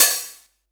Tr8 Open Hat 01.wav